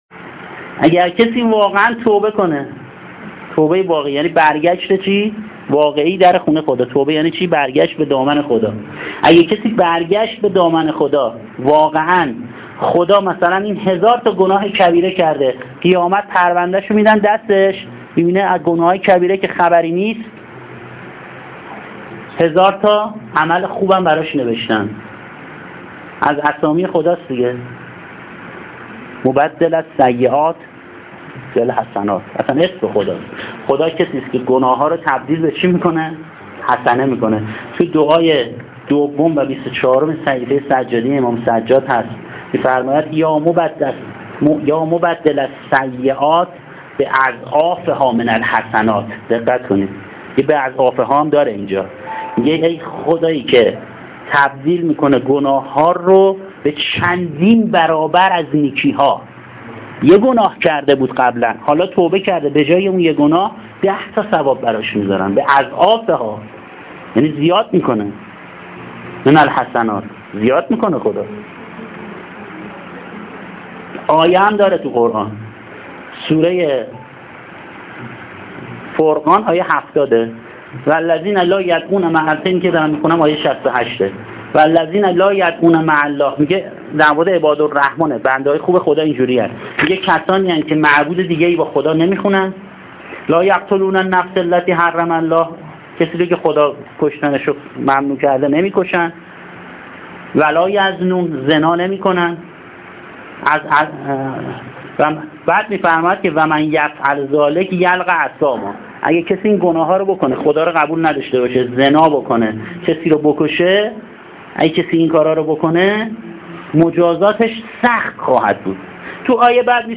گزیده ای از مبحث هیأت متوسلین به جواد الائمه علیه‌السلام شب نوزدهم ماه مبارک رمضان